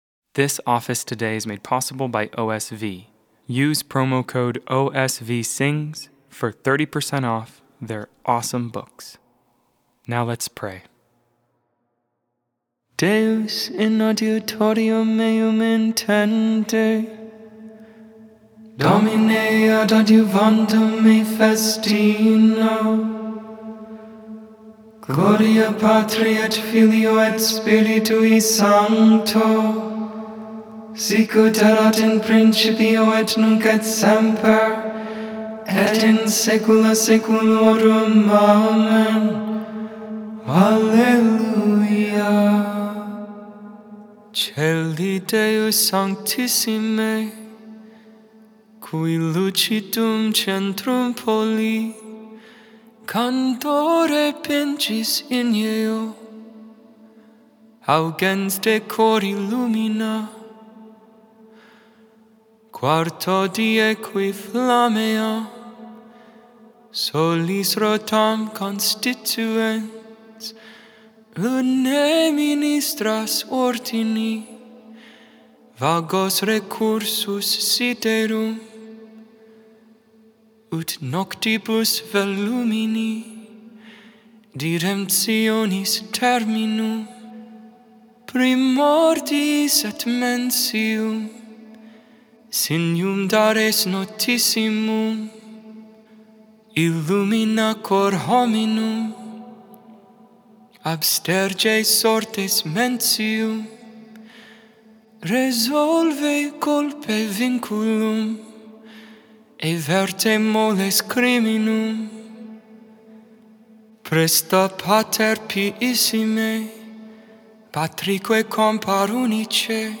Join for morning (Lauds) and evening (Vespers) prayer. All Hymns, Psalms, and Prayer sung.